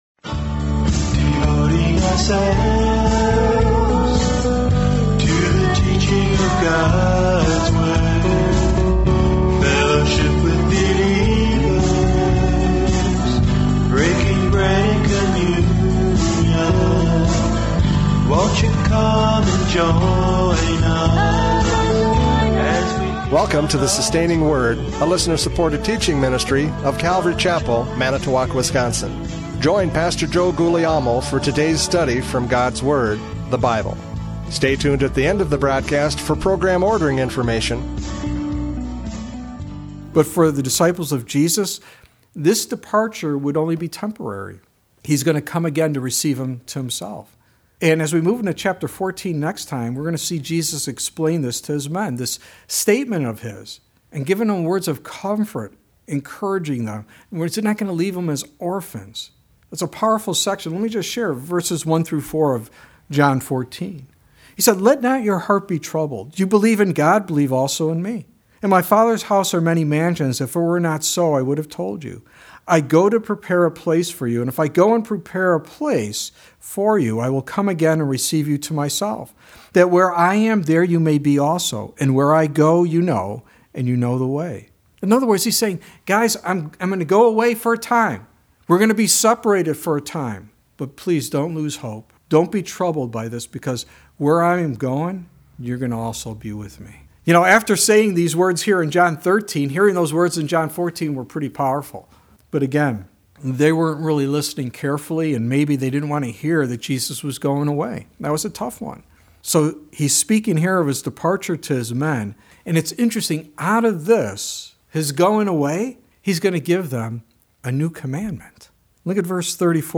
John 13:31-38 Service Type: Radio Programs « John 13:31-38 Listen Carefully!